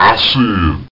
I Said Sound Effect
Download a high-quality i said sound effect.